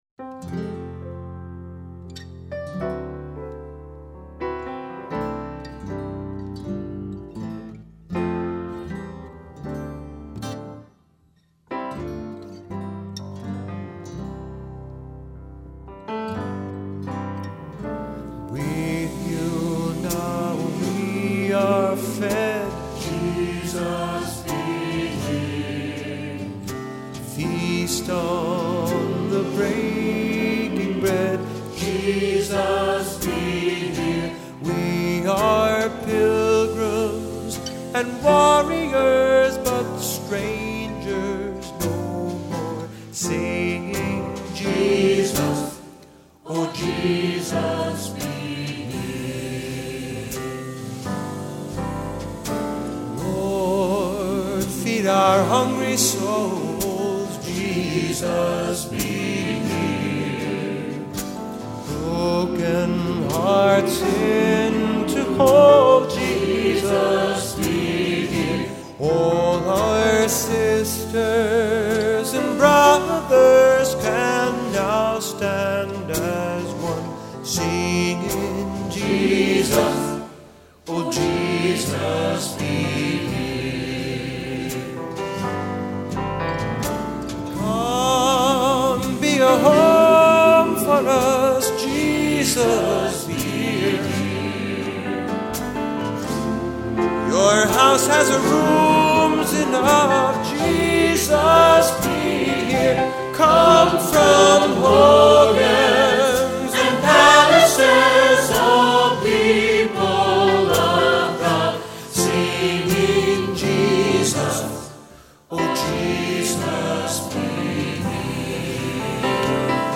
Genre: Catholic.